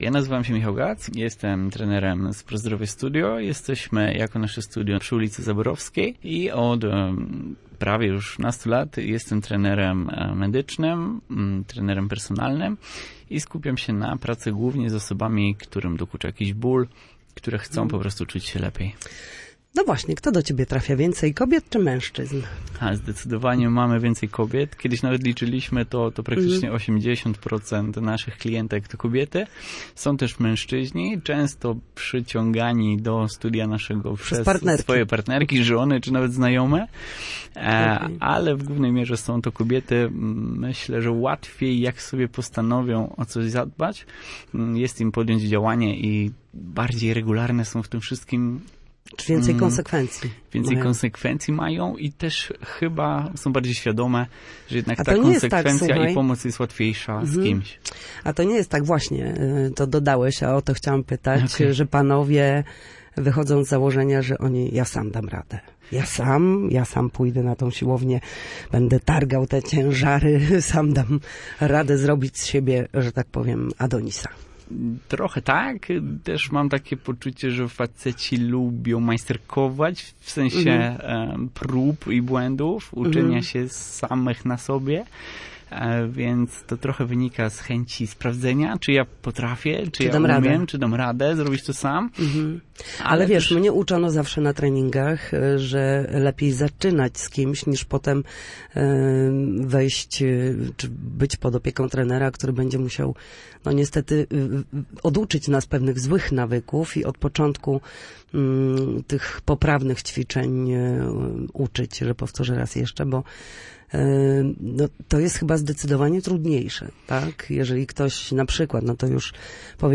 Studio Słupsk Radia Gdańsk